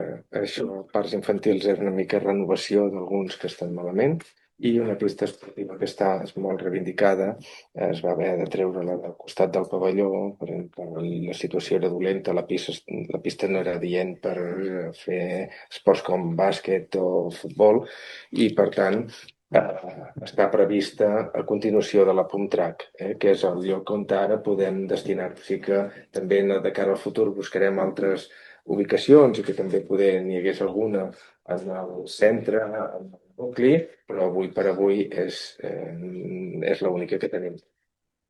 També es preveu la millora de diversos parcs infantils, la remodelació d’una pista esportiva i altres intervencions com la renovació del bar de Ca la Pruna i l’adquisició de nou mobiliari per a equipaments municipals. Ho ha explicat l’alcalde de Pals, Carles Pi.